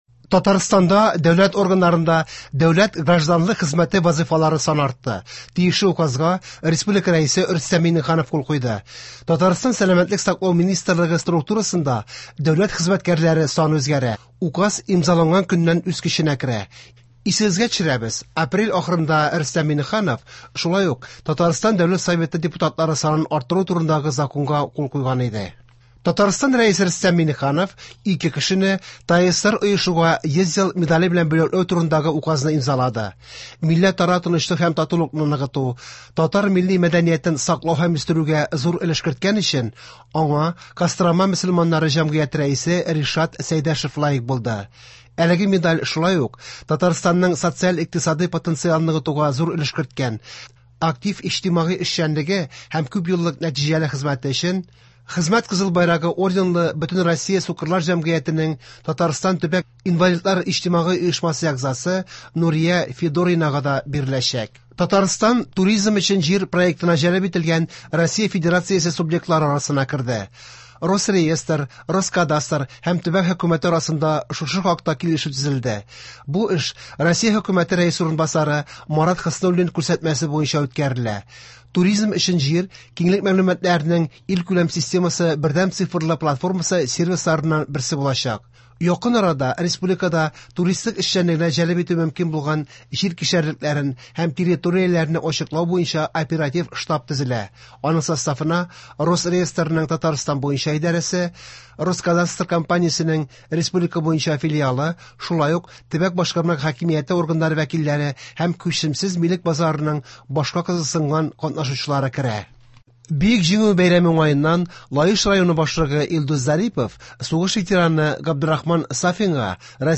Яңалыклар (10.05.23)